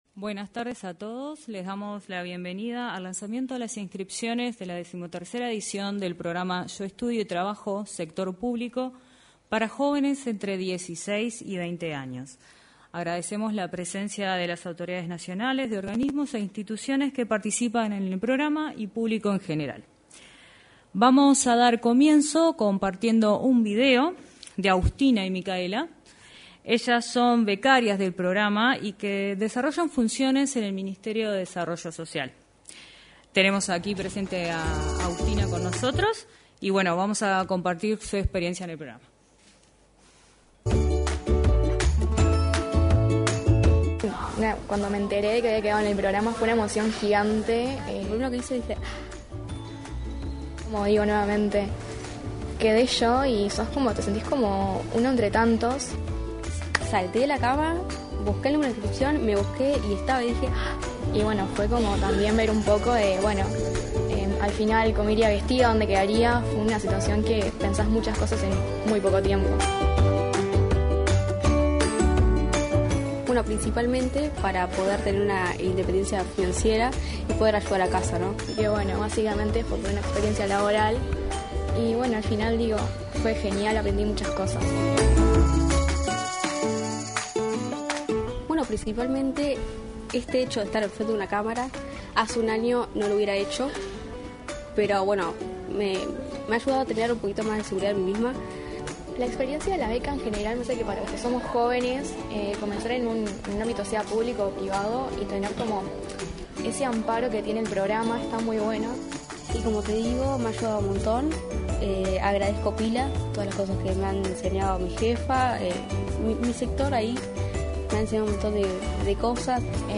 En el salón de actos de Torre Ejecutiva, se concretó el lanzamiento de la 13.ª edición del programa Yo Estudio y Trabajo.
En la oportunidad, se expresaron el subsecretario del Ministerio de Trabajo y Seguridad Social, Daniel Pérez; el director del Instituto Nacional de la Juventud, Aparicio Saravia; el ministro de Educación y Cultura, Pablo da Silveira, y el ministro de Trabajo y Seguridad Social, Mario Arizti.